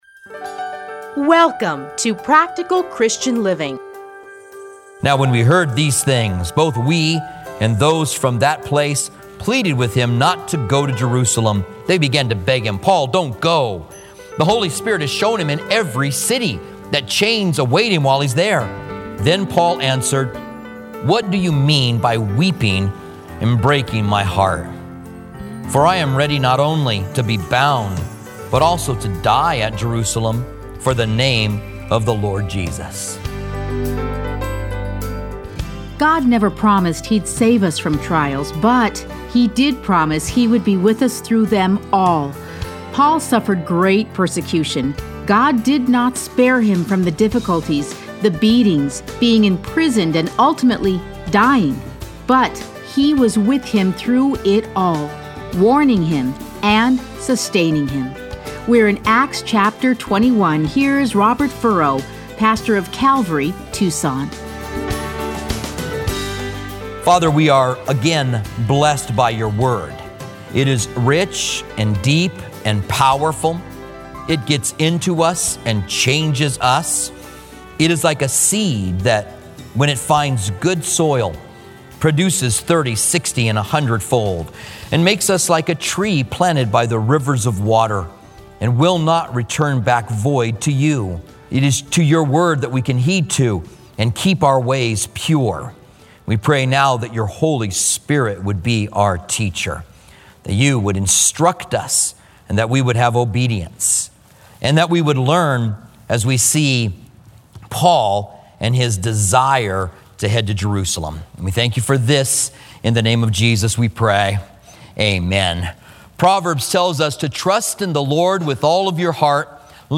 Listen to a teaching from Acts 21 - 22; Playlists Commentary on Acts Download Audio